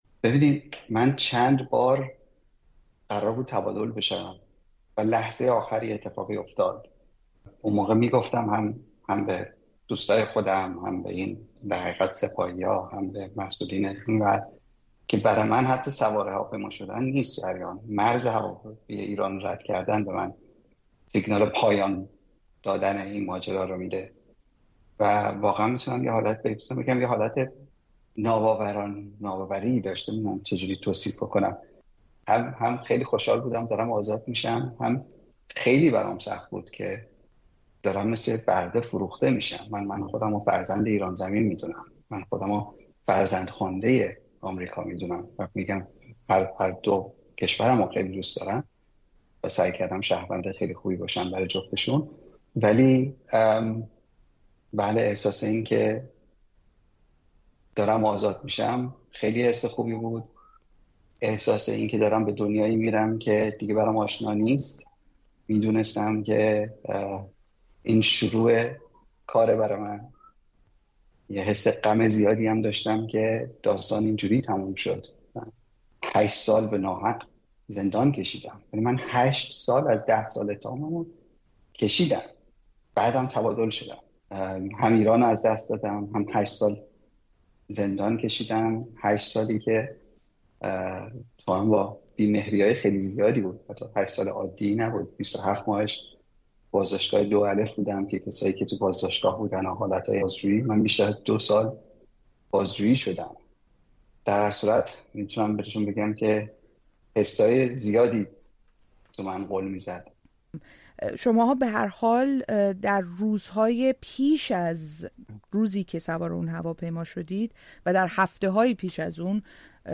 روایت سیامک نمازی از هشت سال «گروگان بودن» در ایران در گفت‌وگو با رادیوفردا